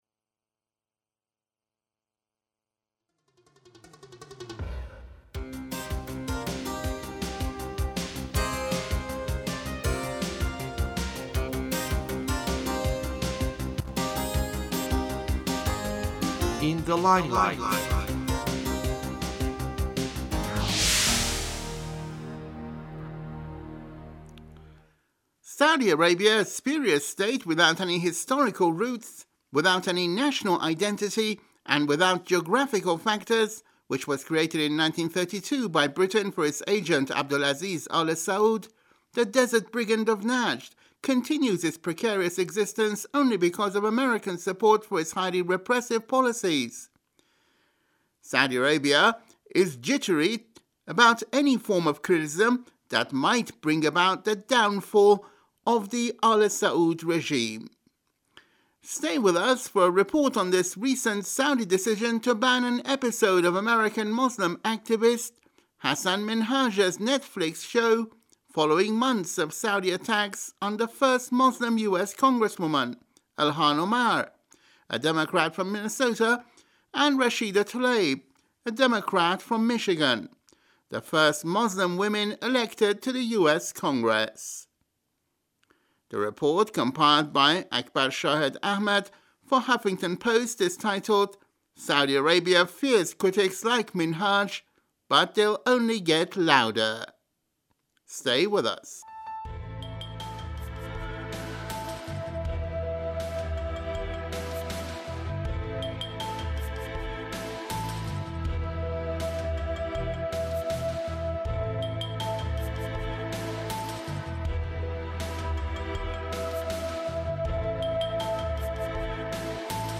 Now we have a report on the recent Saudi decision to ban an episode of American Muslim TV presenter Hassan Minhaj Netflix show, following months of Saudi attacks on the first Muslim US congresswomen – Ilhan Omar (Democrat from Minnesota) and Rashida Tlaib (Democrat from Michigan), the first Muslim women elected to the Congress.